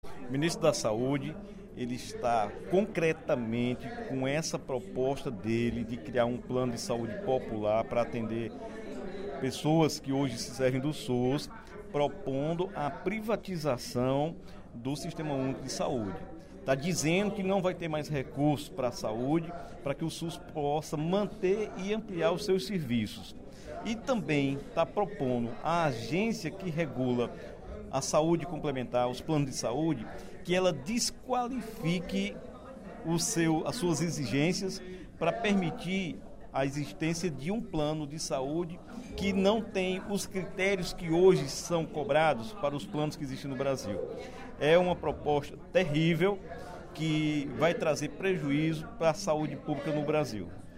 O deputado Dr. Santana (PT) criticou, nesta quinta-feira (07/07), durante o primeiro expediente da sessão plenária, a proposta do ministro da Saúde, Ricardo Barros, de criar um plano de saúde popular.